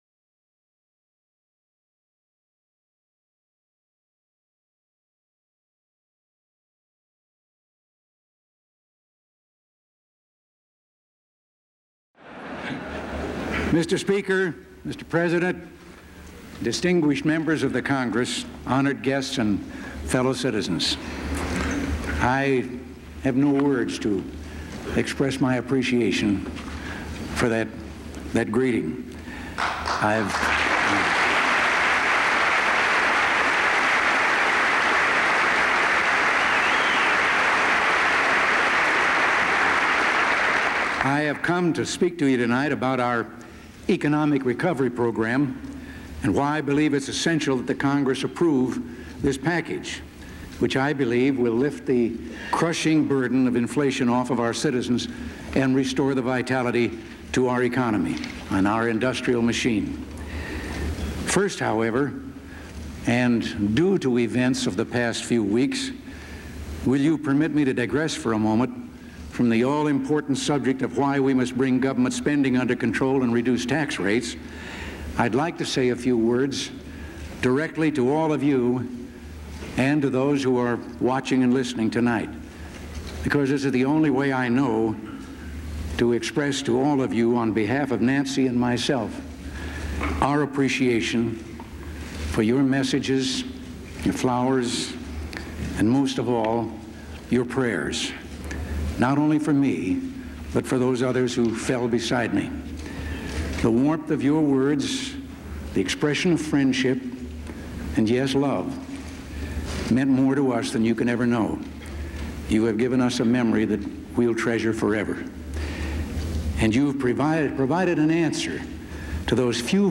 April 28, 1981: Address on the Program for Economic Recovery
Presidential Speeches | Ronald Reagan Presidency